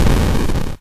collapse_block_fall.ogg